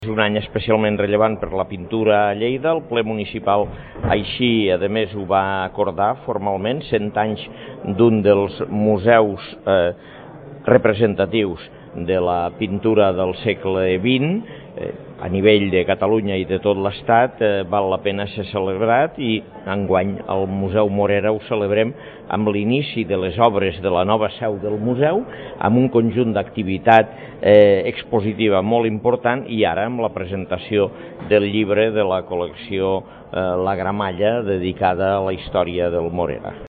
Tall de veu de l'alcalde de Lleida, Àngel Ros, sobre la presentació del llibre que recull els 100 esdeveniments que han marcat la història del Museu d’Art Jaume Morera